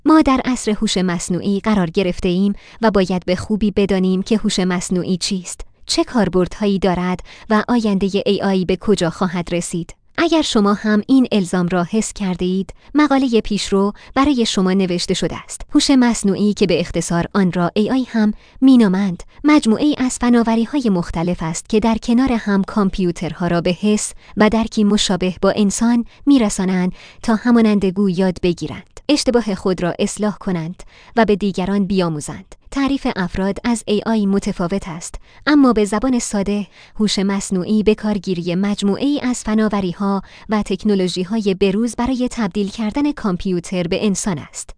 کاربر گرامی این فایل صوتی، با کمک هوش مصنوعی فارسی، ساخته شده است.